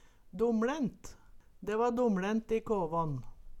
DomLint blir og bruka Høyr på uttala Ordklasse: Adjektiv Attende til søk